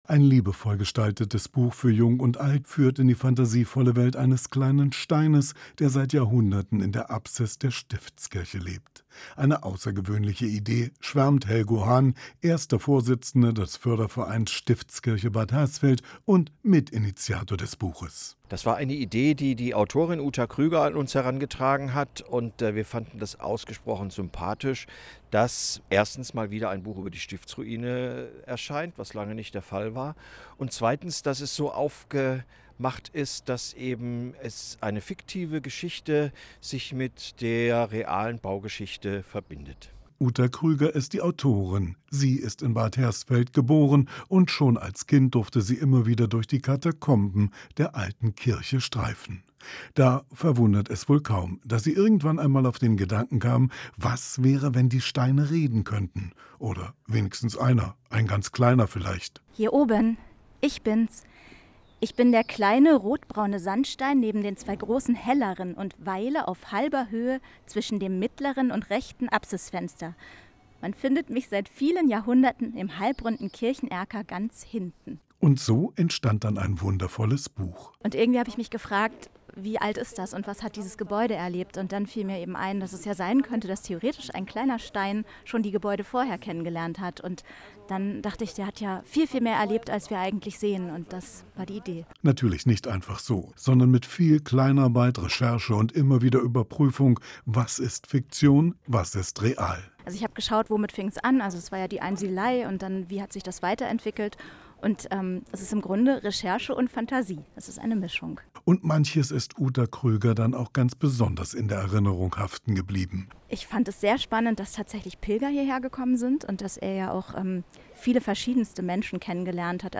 berichtet über die Buchveröffentlichung
HR_Bericht_Buch_Stiftsruine.MP3